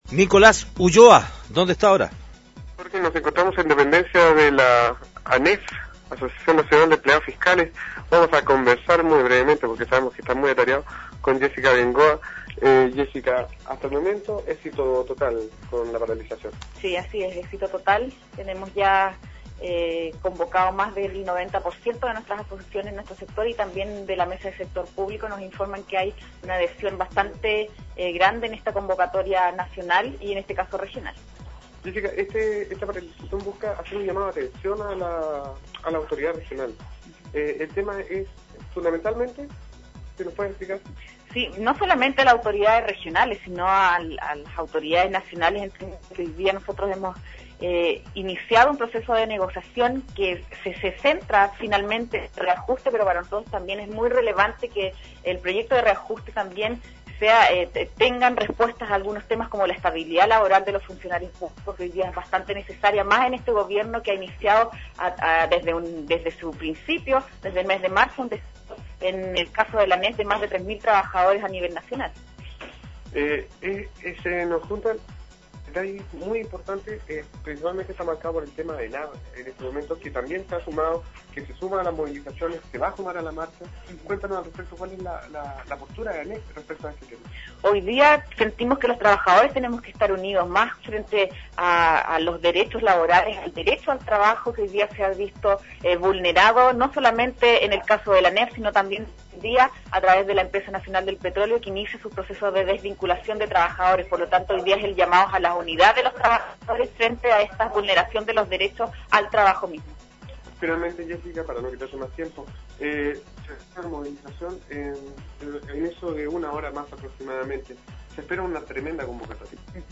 Entrevistas de Pingüino Radio